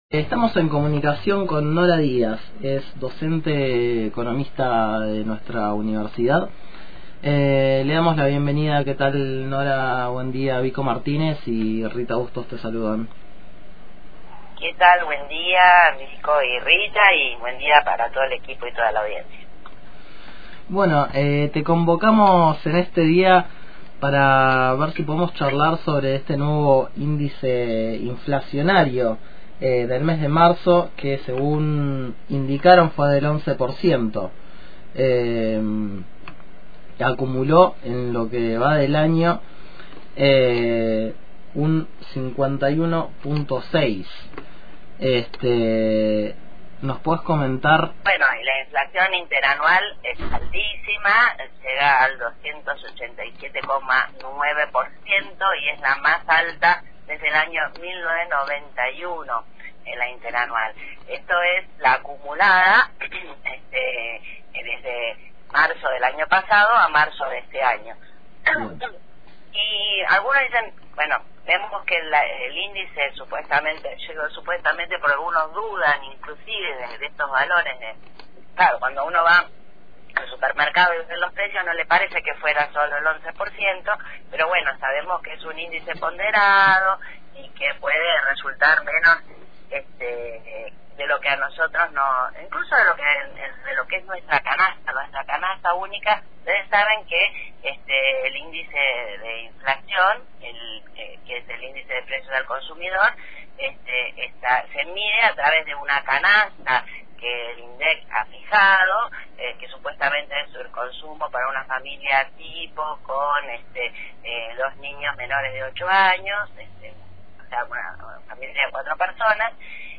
El tema convocante fue el análisis tras la publicación del índice de inflación para el mes de marzo que rondó el 11%. Escuchá la entrevista completa desde acá: